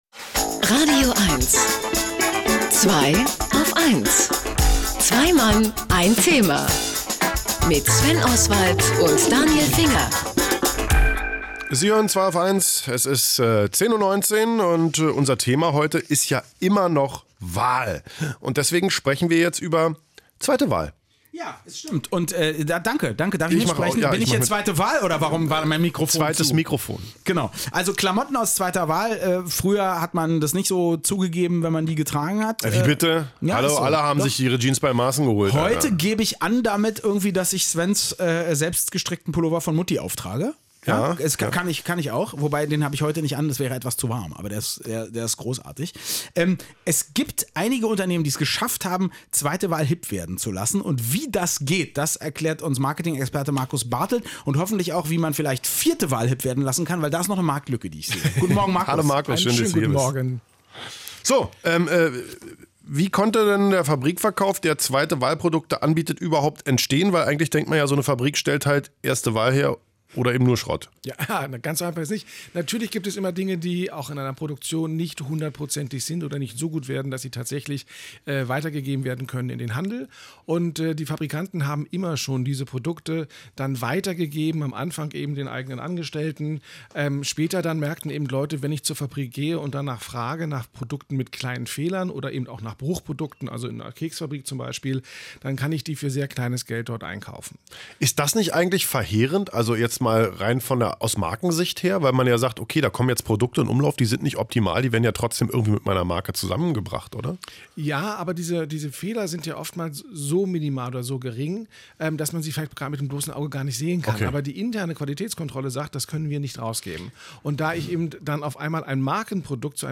Es geht also um FOC, um Factory Outlet Center, und zu diesem Thema war ich zu Gast bei Zwei auf eins im radioeins-Studio: